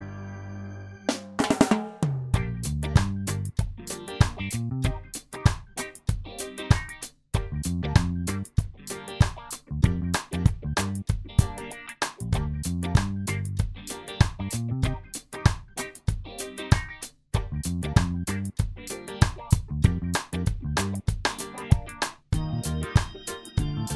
Minus Wah Solo Reggae 4:11 Buy £1.50